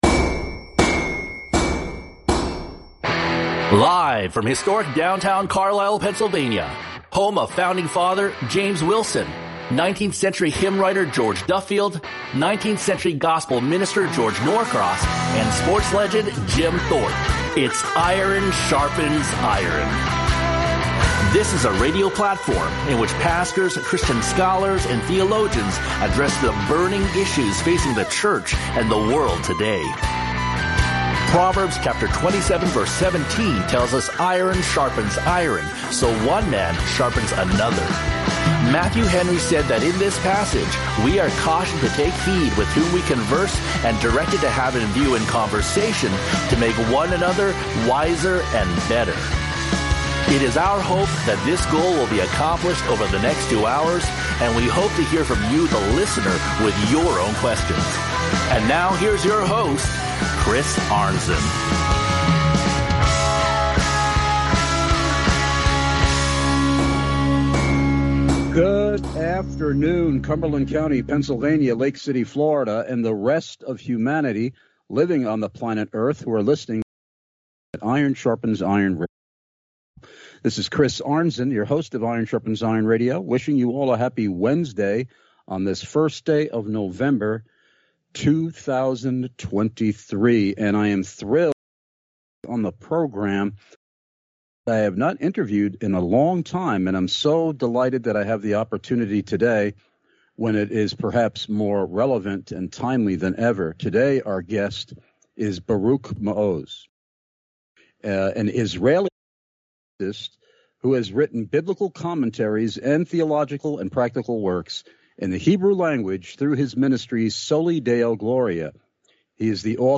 NOTE: We apologize for the audio quality of this interview. Technical issues during the time of the interview caused the audio to drop out intermittently.